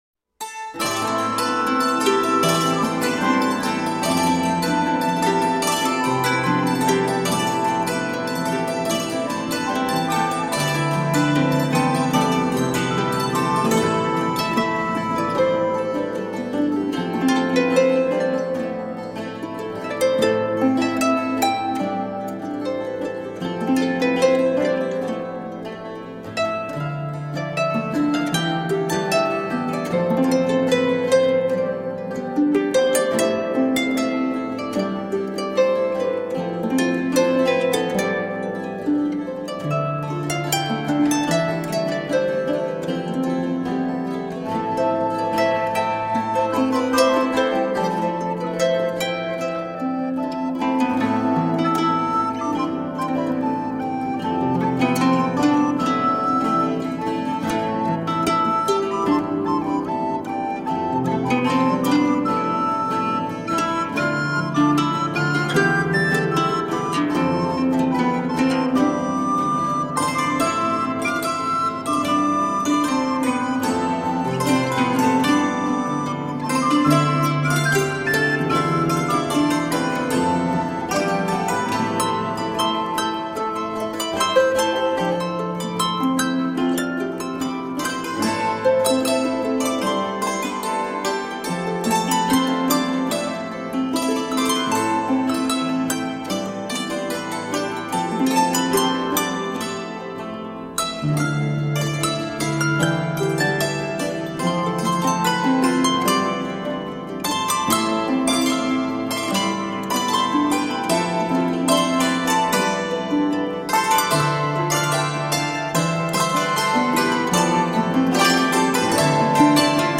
Timeless and enchanting folk music for the soul.
Tagged as: World, Folk, Christmas, Harp